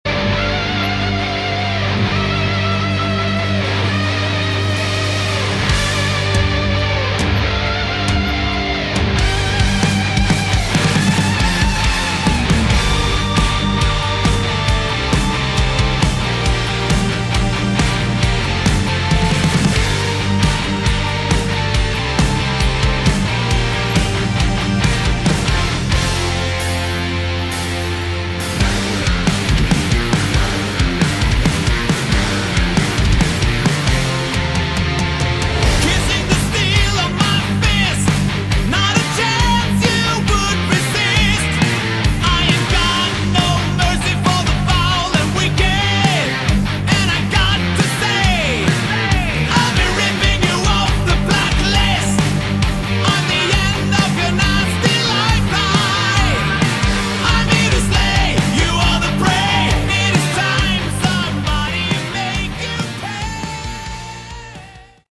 Category: Melodic Metal
rhythm, lead and acoustic guitars
drums and percussion
vocals
bass guitar
A great harder edged melodic rock.